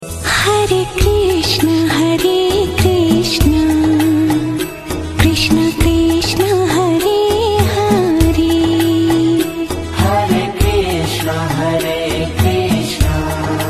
Chant Hare Krishna Maha Manta Sound Effects Free Download